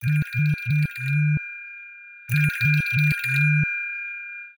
Loose_change.ogg